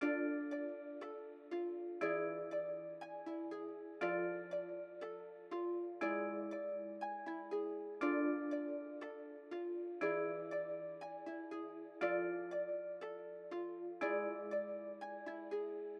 I can Tell 120bpm.wav